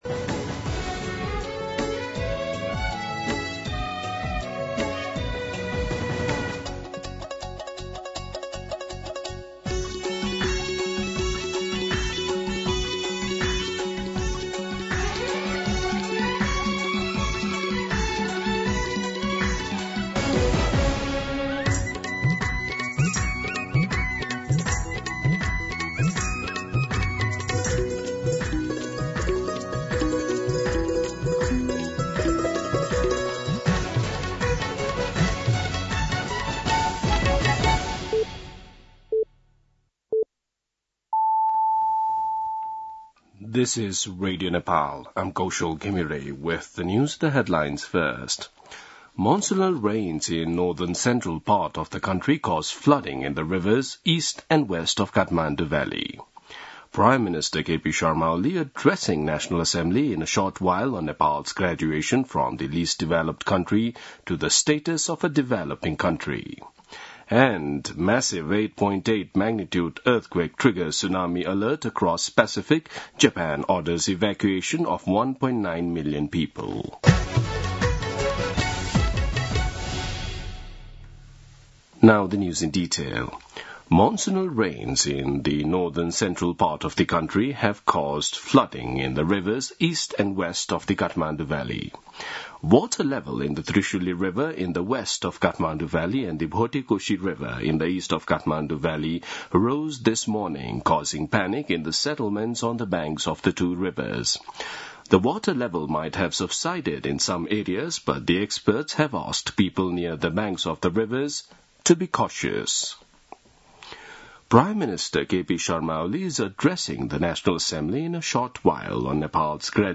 दिउँसो २ बजेको अङ्ग्रेजी समाचार : १४ साउन , २०८२